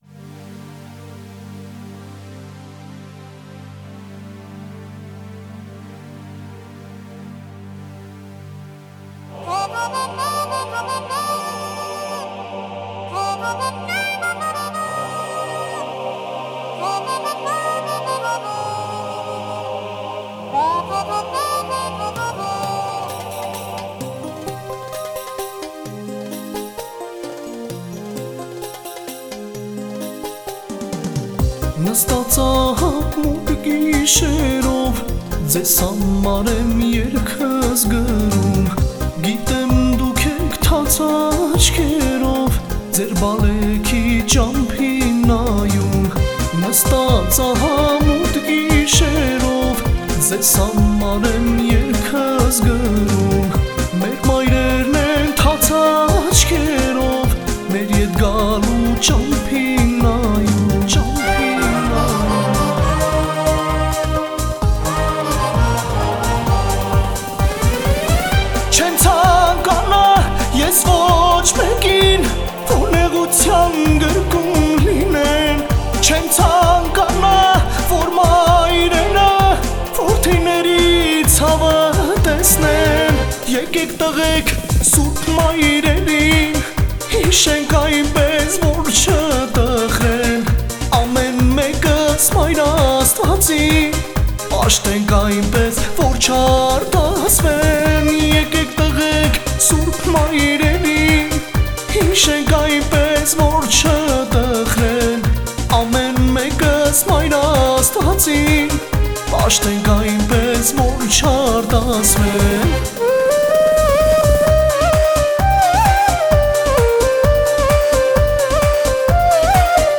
tuyn rabiz erg